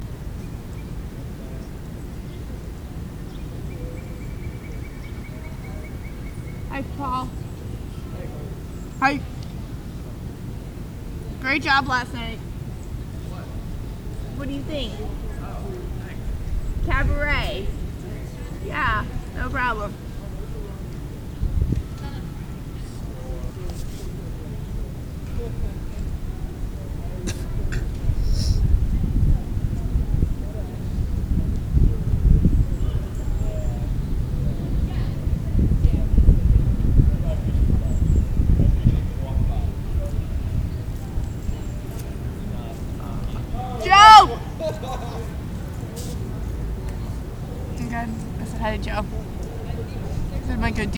Peaceful – Hofstra Drama 20 – Sound for the Theatre
1) Sitting on the grass outside of Lowe.
2) Several birds chirping, people talking, footsteps, ambient noise and coughing, sniffling.
field-recording.mp3